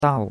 dao4.mp3